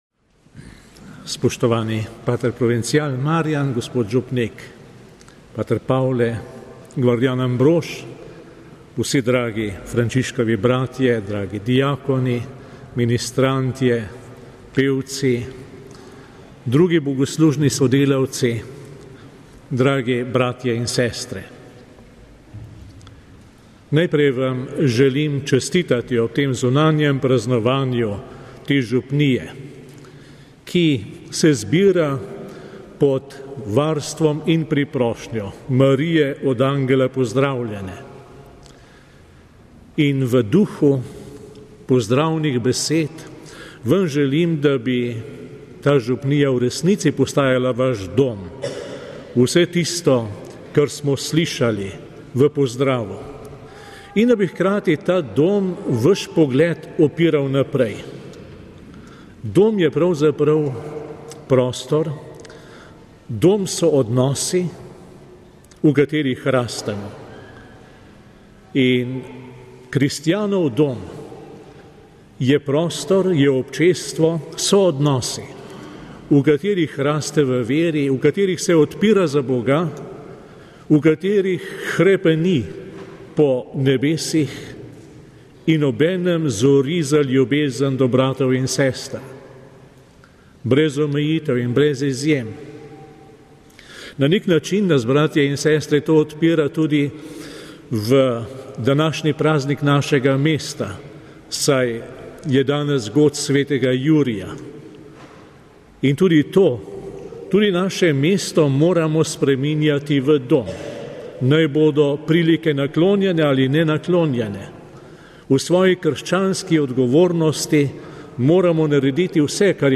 LJUBLJANA (nedelja, 23. april 2017, RV) – Ljubljanski nadškof metropolit msgr. Stanislav Zore je v župnijski cerkvi Marijinega oznanjenja v Ljubljani na belo nedeljo oz. nedeljo Božjega usmiljenja vodil zunanjo slovesnost župnijskega praznika Marijinega oznanjenja.
Pridiga